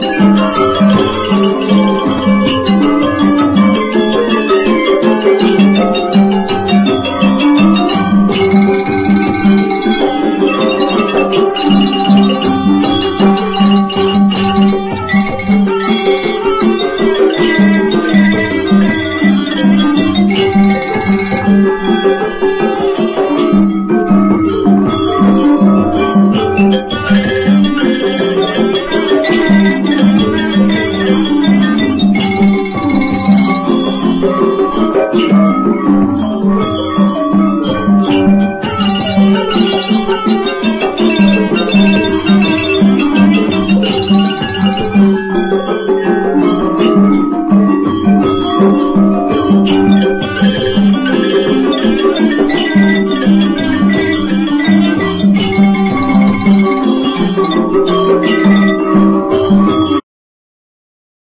WORLD / OTHER / FRENCH / 60'S BEAT / GIRL POP / FUNK
選りすぐりのフレンチ・グルーヴをコンパイル！ フランス産グルーヴを60年代の音源を中心にセレクト！